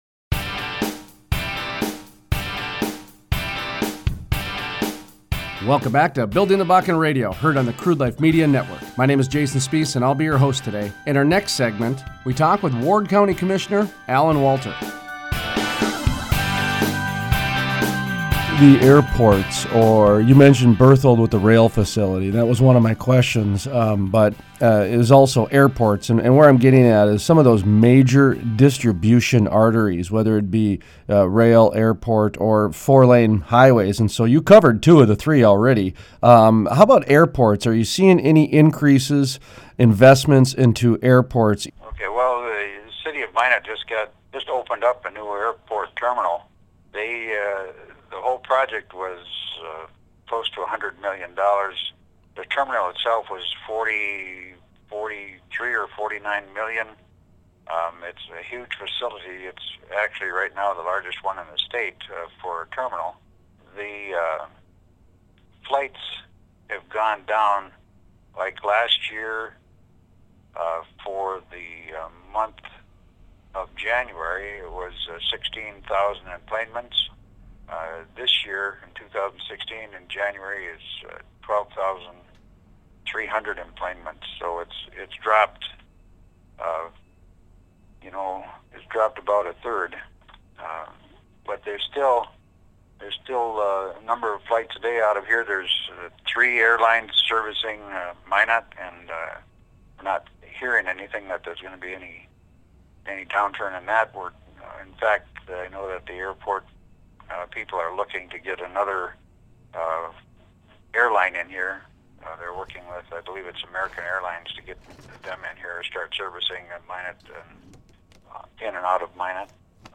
Interviews: Alan Walter, Ward County Commissioner